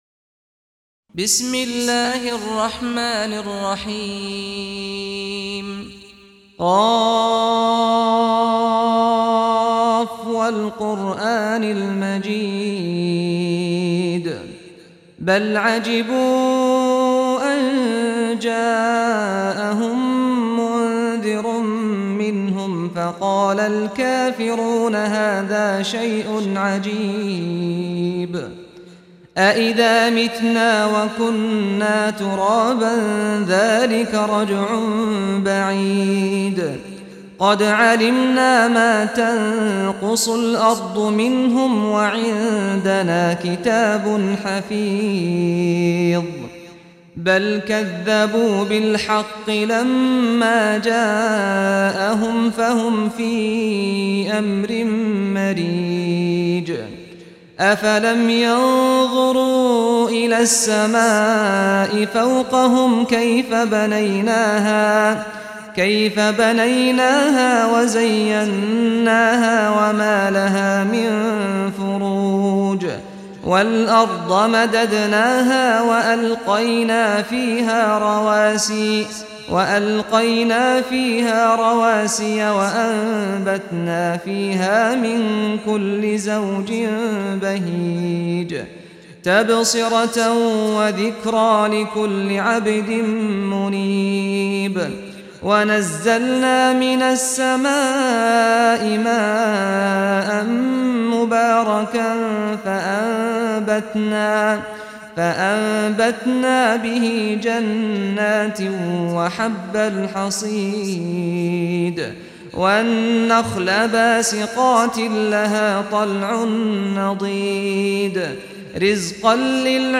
سورة ق - سعد بن سعيد الغامدي (صوت - جودة فائقة. التصنيف: تلاوات مرتلة